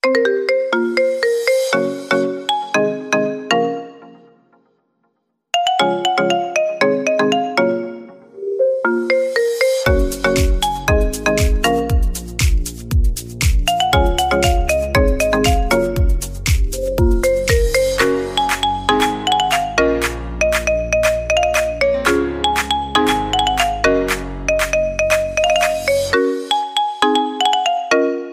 Kategori Marimba Remix